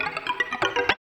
78 GTR 4  -R.wav